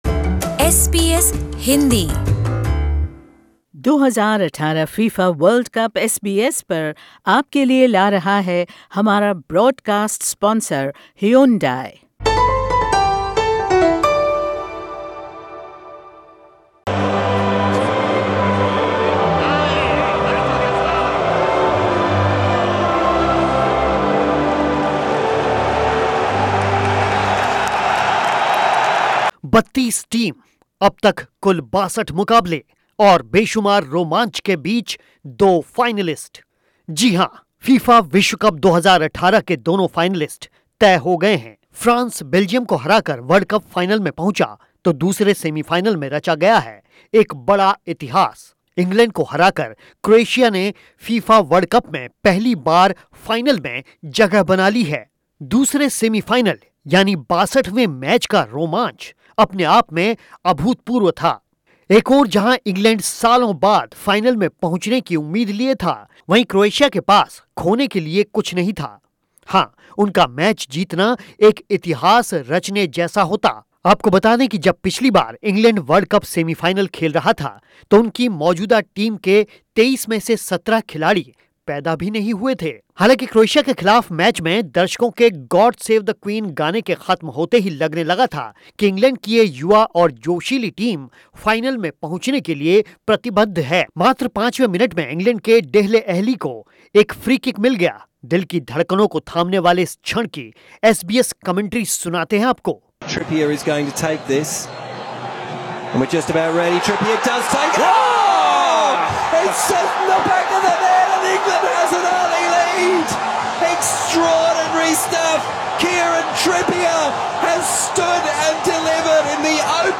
लेकिन आखिर कैसा रहा सेमीफाइनल का रोमांच सुनिए ये रिपोर्ट